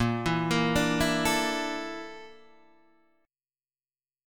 A# Major 7th Flat 5th